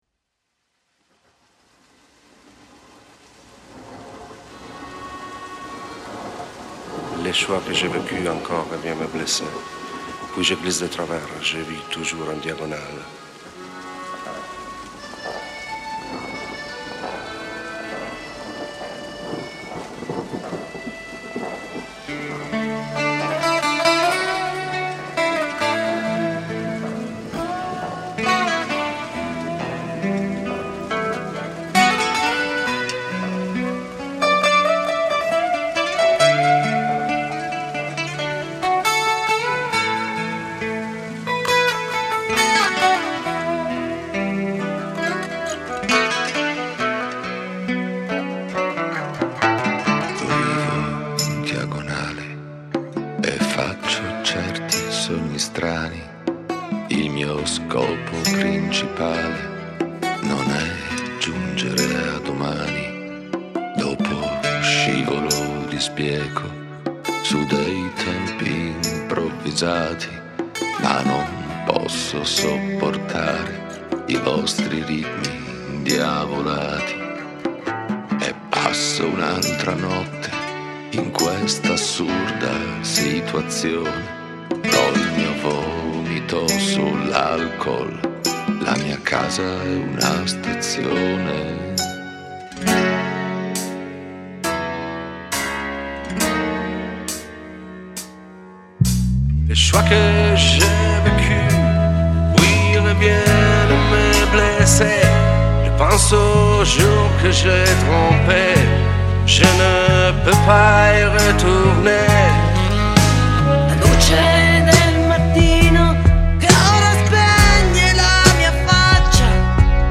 una band acustica
Lead Vocal
Guitars
Percussions
Bass